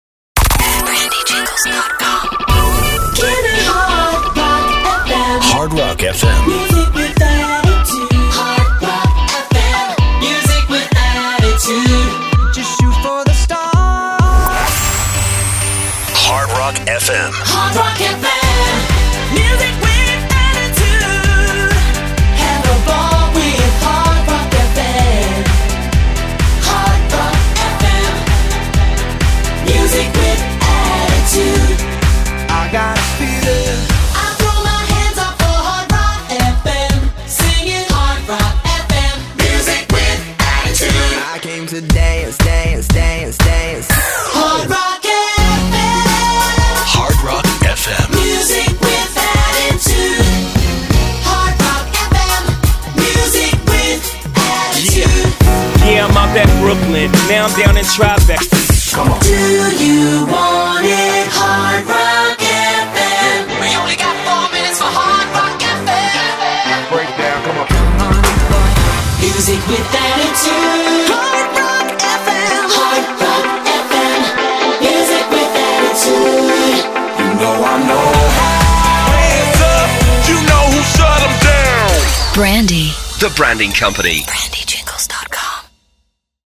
Power Intro’s
het pop cross-over station van Indonesië.